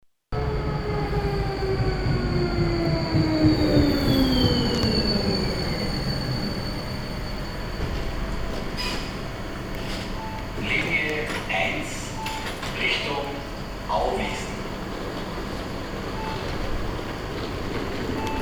LISA – Liniensprachansage:
Man erfährt über Außenlautsprecher am Fahrzeug die Liniennummer und das Endziel.
Hörbeispiel Straßenbahnlinie 1: